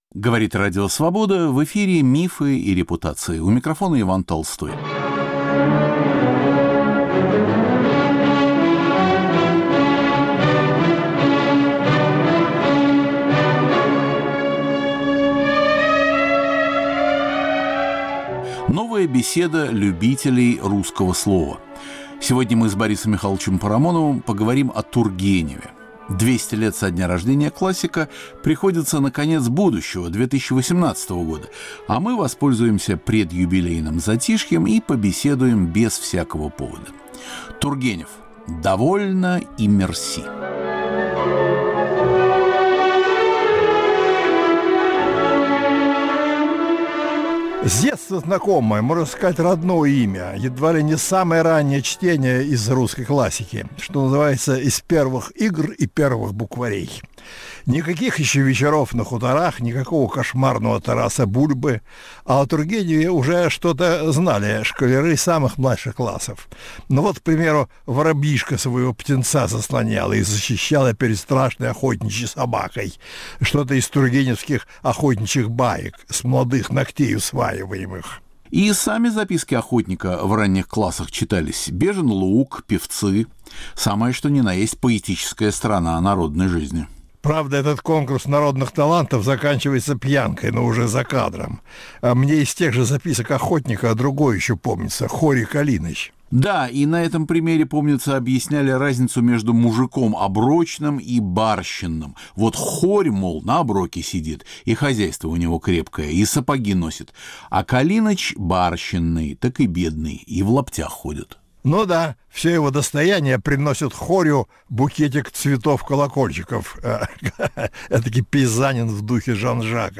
Борис Парамонов и Иван Толстой говорят об Иване Сергеевиче Тургеневе. Передача названа "Довольно и мерси".